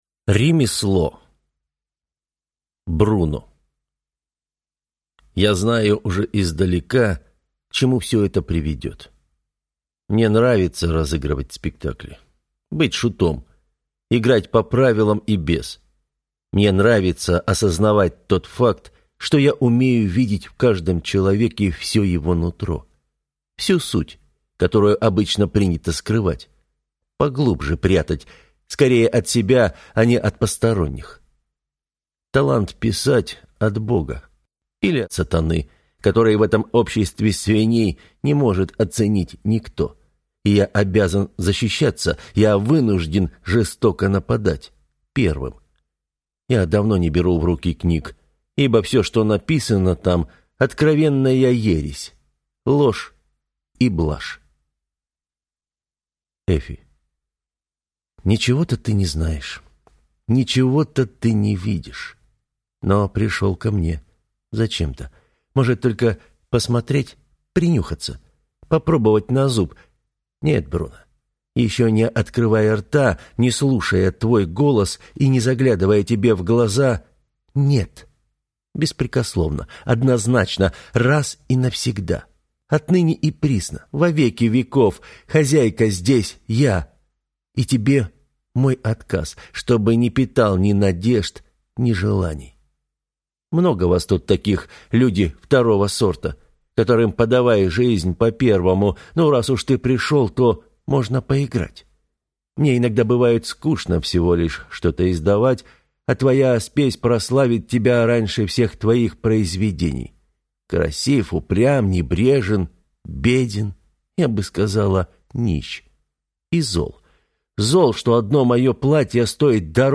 Аудиокнига Ремесло | Библиотека аудиокниг